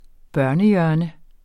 Udtale [ ˈbɶɐ̯nə- ]